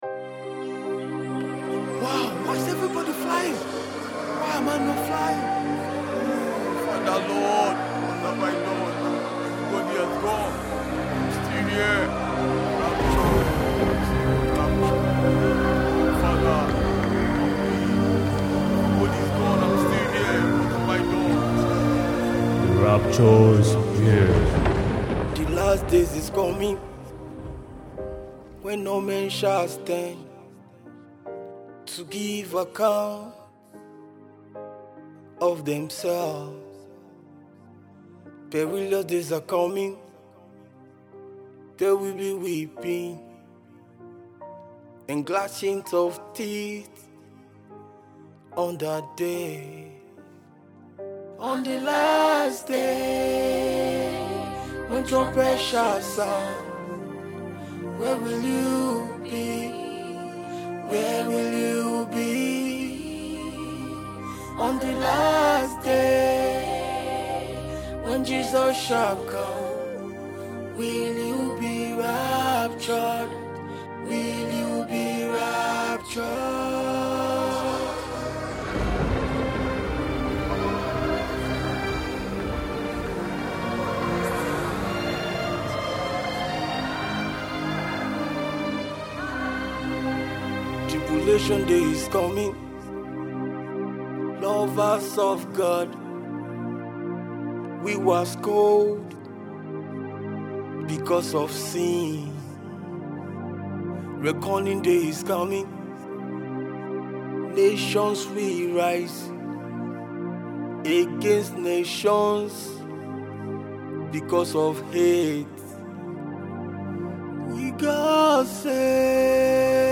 GOSPEL
” a powerful evangelical song that is strategic.